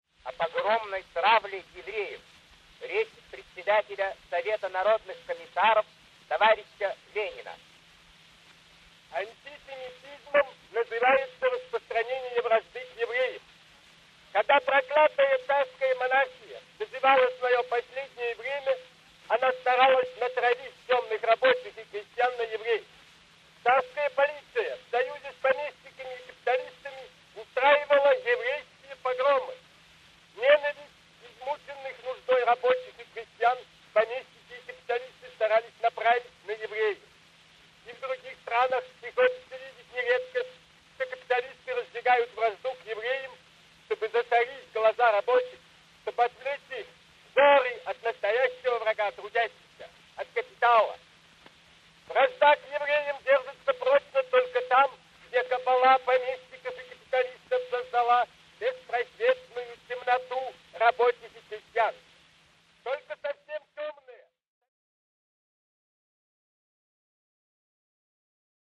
On trouve aussi quelques documents parlés : discours d’hommes politiques –